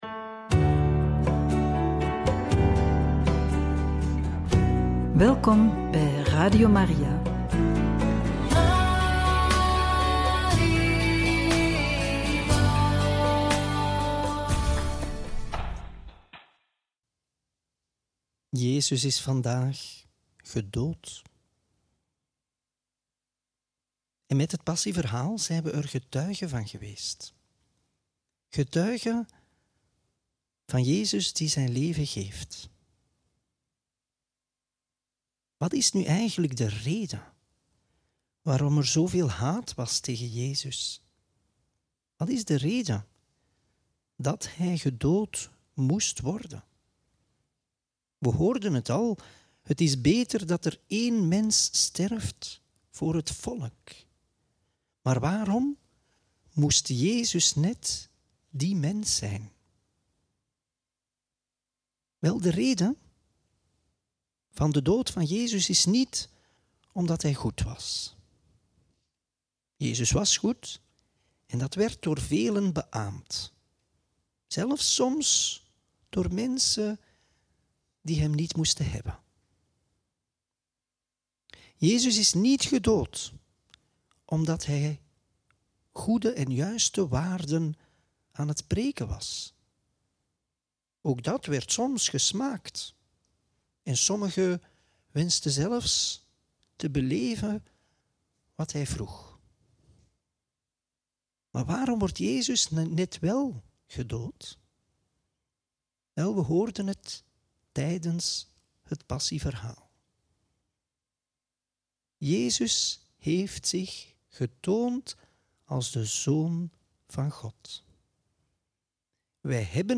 Homilie op Goede Vrijdag - Joh. 18, 1-40; 19, 1-42